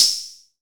• Subtle Reverb Ride Cymbal Drum Sound E Key 08.wav
Royality free ride cymbal sound sample tuned to the E note. Loudest frequency: 5505Hz
subtle-reverb-ride-cymbal-drum-sound-e-key-08-IHH.wav